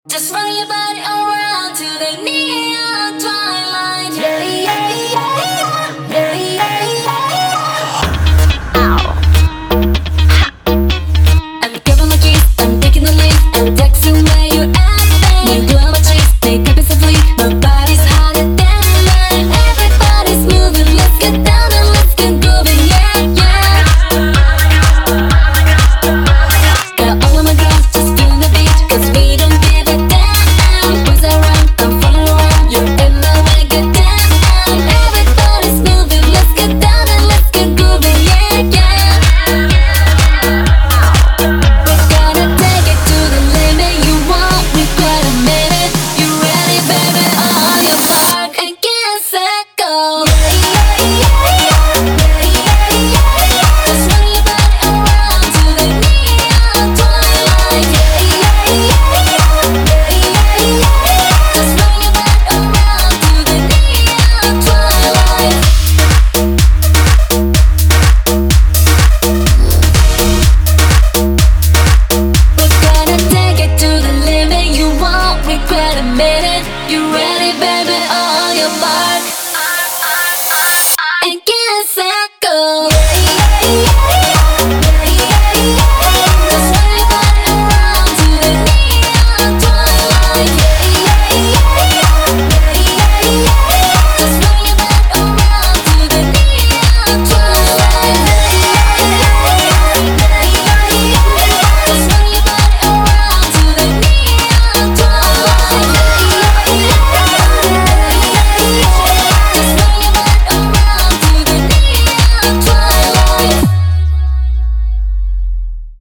BPM62-125